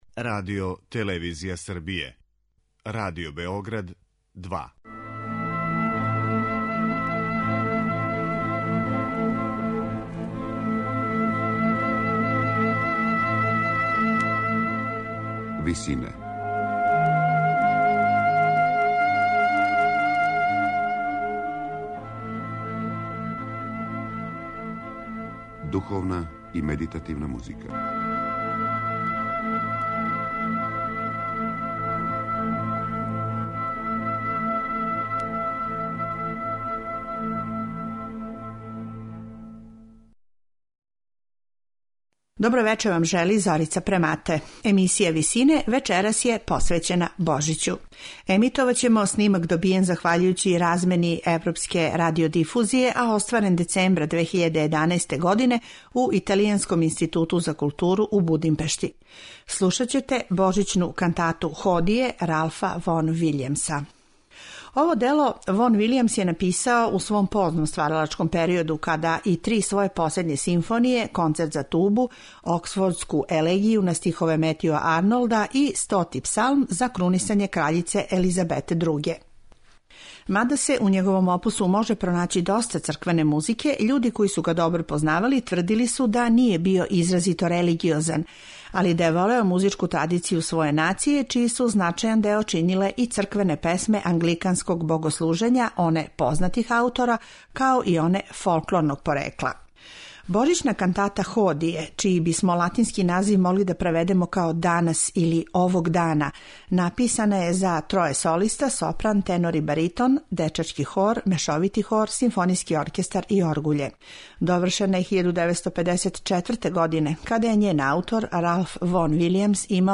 емитоваћемо божићну кантату „Ходие'
Извођачи су: солисти, Дечији хор, Мешовити хор и Симфонијски оркестар Мађарског радија.